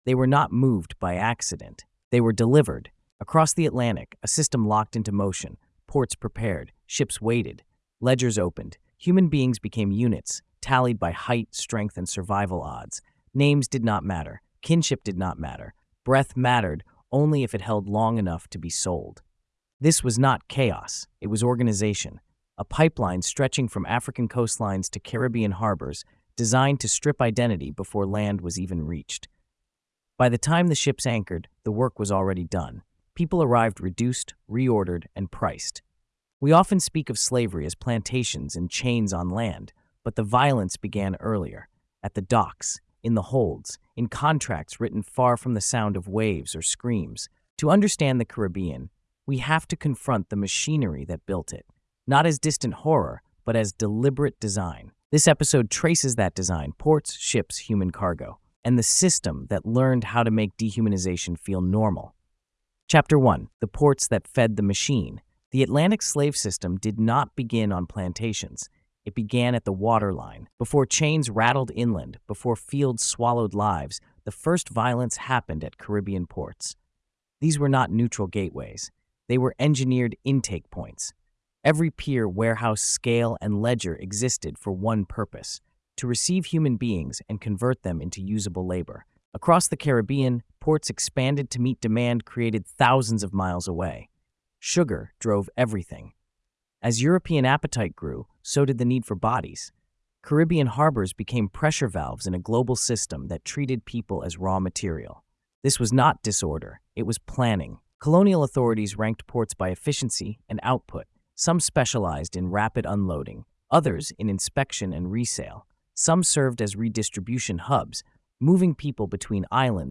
Told with a documentary base and a restrained host presence, the story centers structure over spectacle and reveals how cruelty hid behind order, paperwork, and profit.